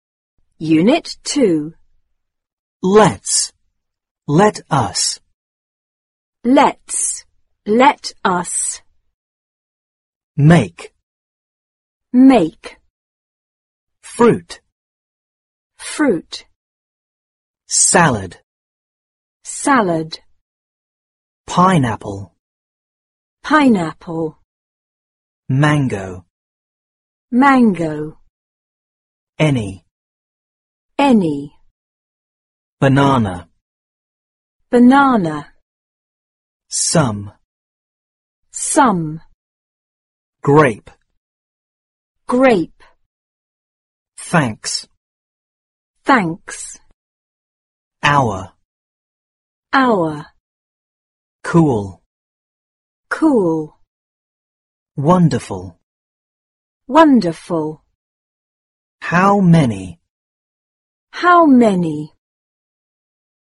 四年级英语上Unit 2 单词.mp3